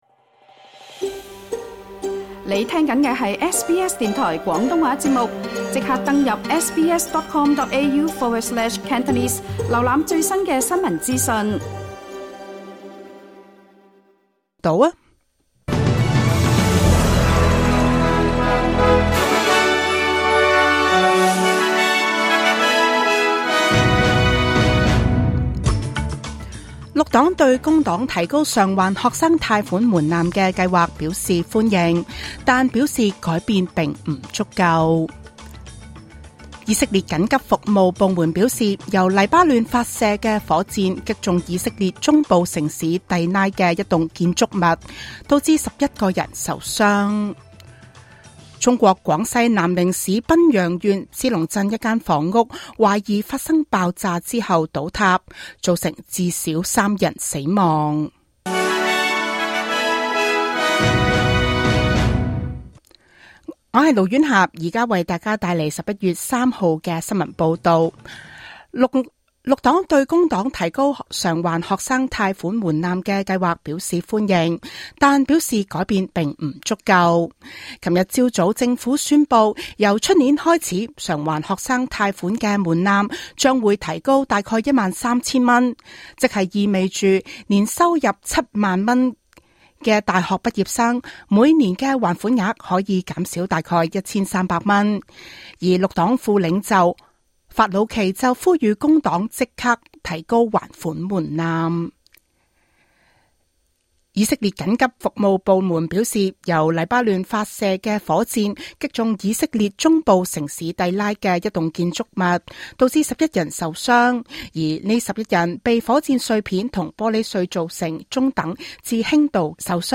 2024 年11 月 3日 SBS 廣東話節目詳盡早晨新聞報道。